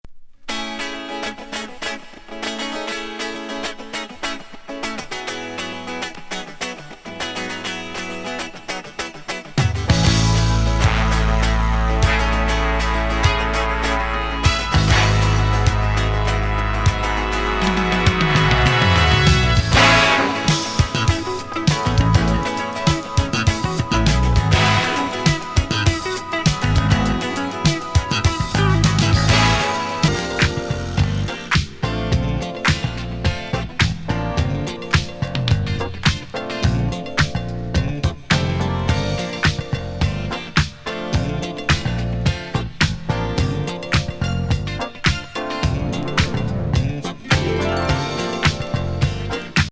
テレキャス・カッティングからの爽快
ディスコティーク